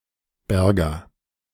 The pronunciation of the English name may sometimes be /ˈbɜːrər/ BUR-jər, following the French phonetics [bɛʁʒe] ; the German one is [ˈbɛʁɡɐ]
De-Berger.ogg.mp3